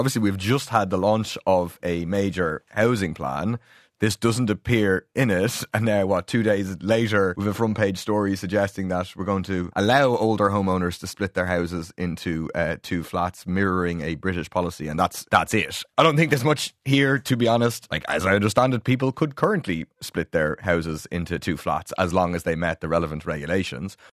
People Before Profit Solidarity TD, Paul Murphy, says the idea doesn’t offer anything new to the housing crisis: